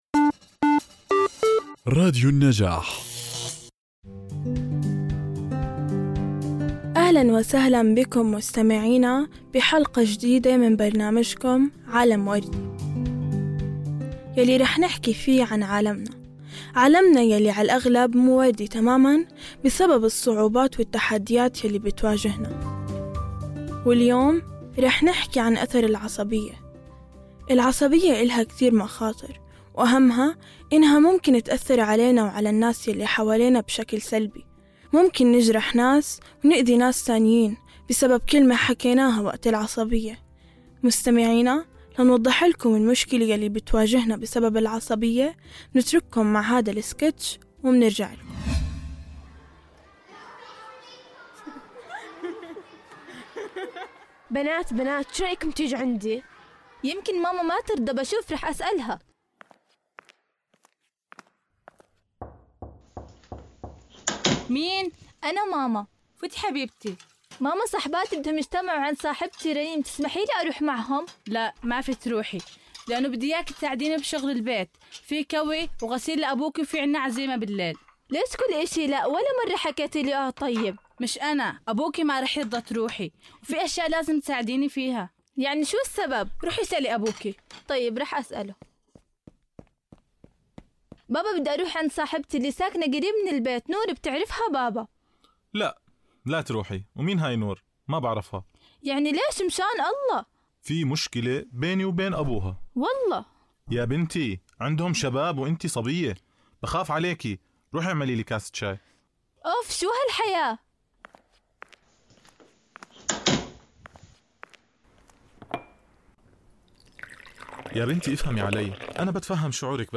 من خلال سكيتش تمثيلي مبتكر ومؤثر، تقدم الحلقة صورة حية للتحديات التي تواجهها العلاقات بسبب العصبية، يشتمل السكيتش على أحداث وقصص حقيقية تم تجسيدها بطريقة تعكس الواقع.
بودكاست عالم وردي هو برنامج مميز يقدمه مجموعة من اليافعات المشاركات ضمن مشروع نبادر في مركز حكاية لتنمية المجتمع المدني، يتميز هذا البودكاست بتضمينه سكيتشات تمثيلية درامية تعكس تجارب الفتيات واليافعات في مواجهة قضايا مثل التمييز بين الذكور والإناث، عدم الثقة بالبنات، تأثير السوشل ميديا على حياتهن، العنف ضد البنات والعصبية.